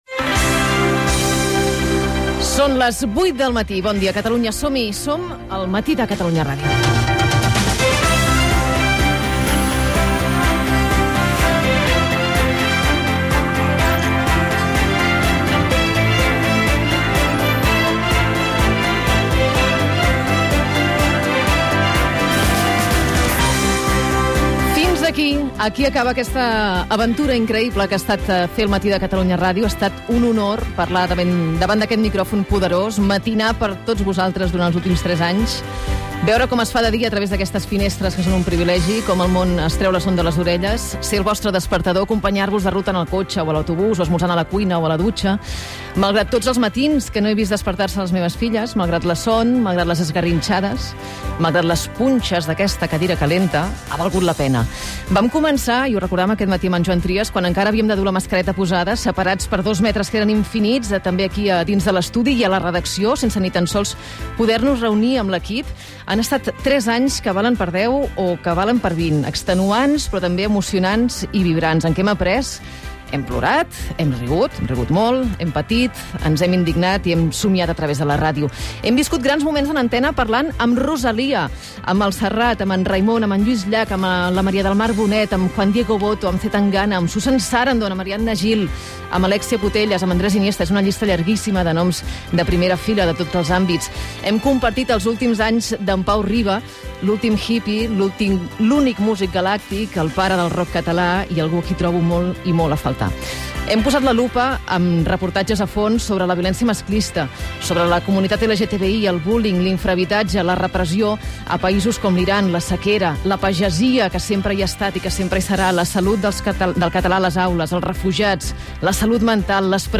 Sintonia del programa, hora i editorial comiat de Laura Rosel. Valoració del fet, agraïment a l'equip i indicatiu del programa
Info-entreteniment